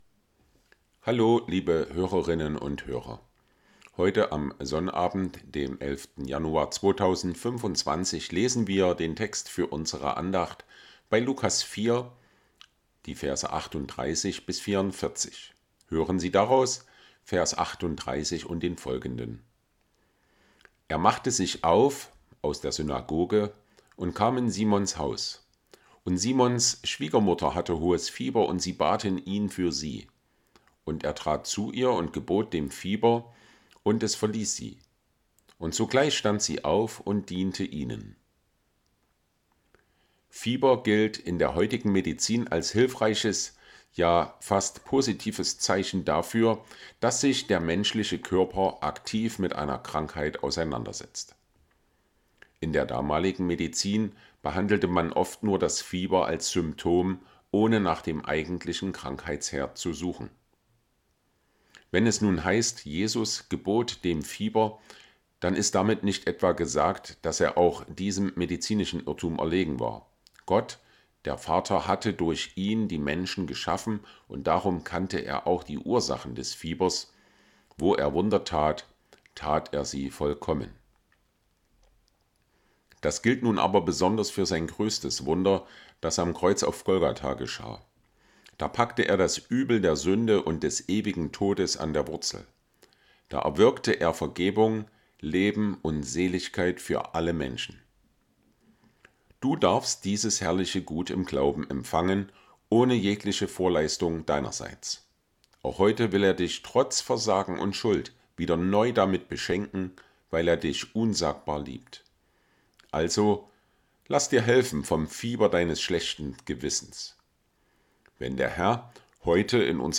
Tägliche Andachten aus dem Andachtsheft der Ev.-Luth. Freikirche